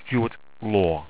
Pronounced
STEW-IT LOR